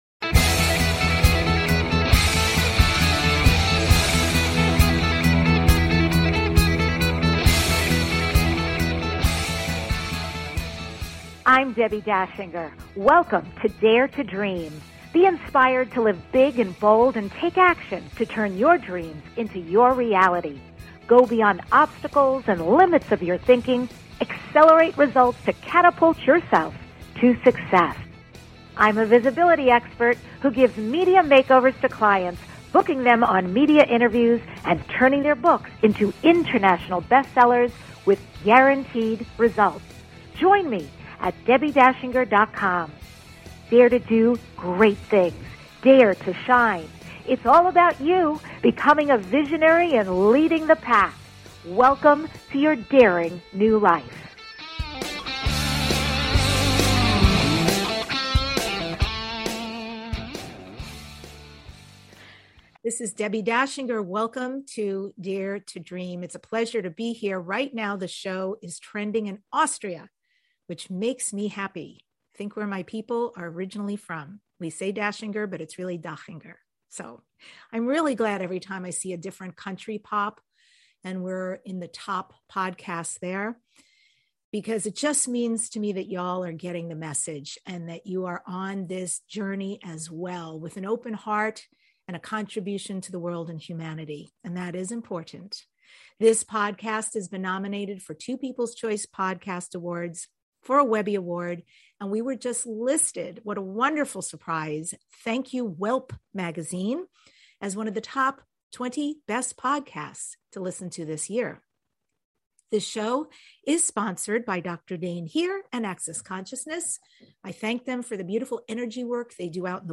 The award-winning DARE TO DREAM Podcast is your #1 transformation conversation.